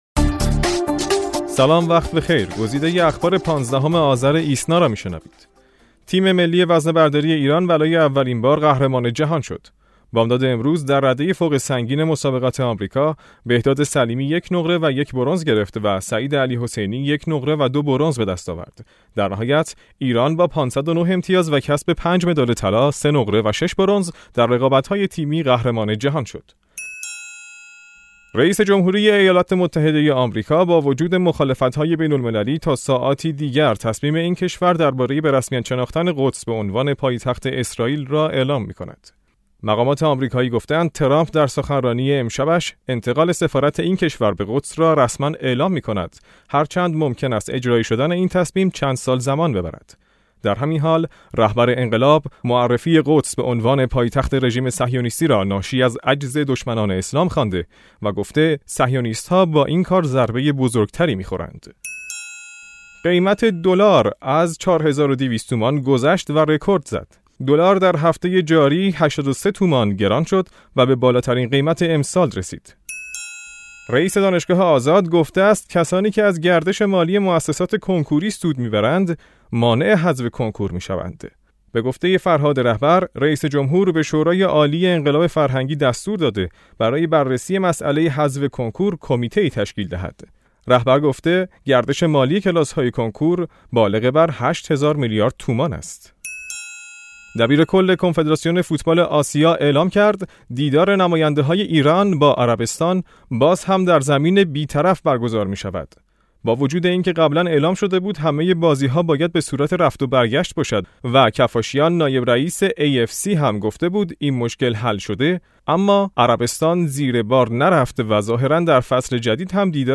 در میان مهمترین رویدادهای ایران و جهان که در ساعات گذشته اتفاق افتاده، برخی اخبار بیش از دیگر خبرها اهمیت یافته‌اند. در این بسته صوتی «ایسنابیست» برخی از مهمترین خبرهای امروز - ۱۵ آذر ۱۳۹۶ - را مرور می‌کنیم.